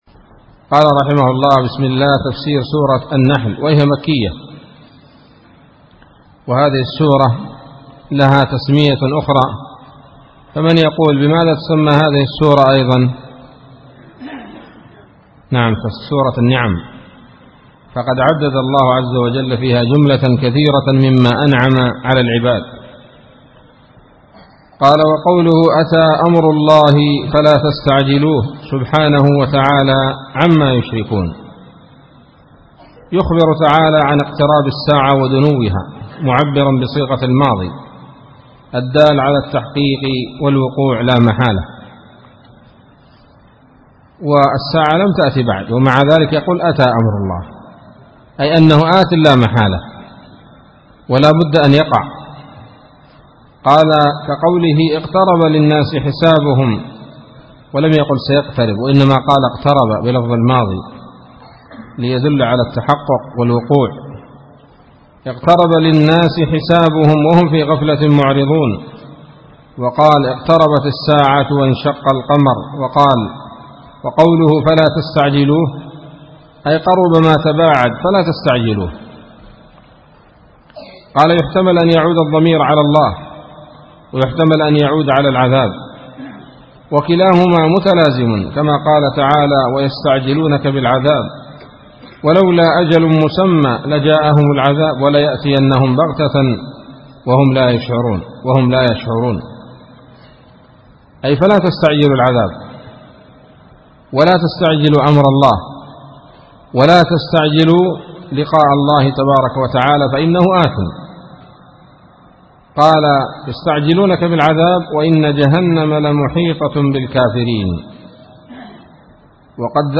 الدرس الأول من سورة النحل من تفسير ابن كثير رحمه الله تعالى